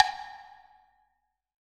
6HWOODBL.wav